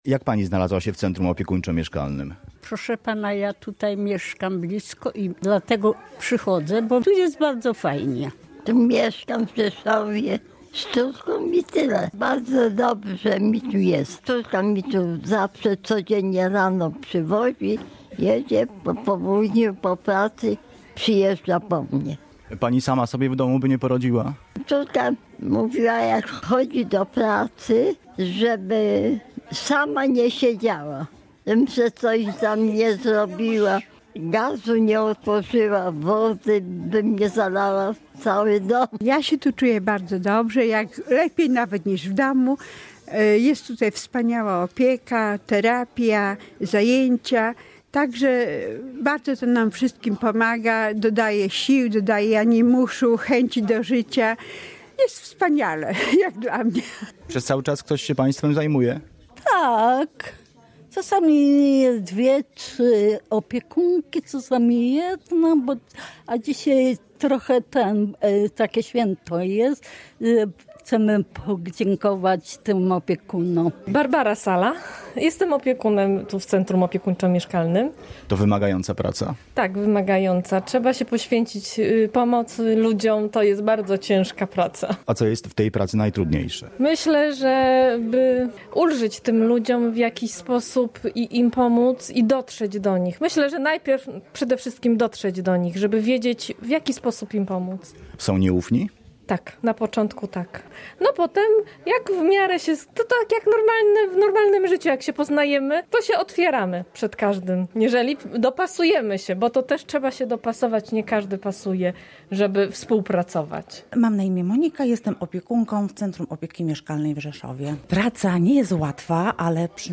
We wtorek (12 sierpnia) zorganizowano tutaj obchody Dnia Pracownika Opieki nad Osobami Starszymi.
Relacja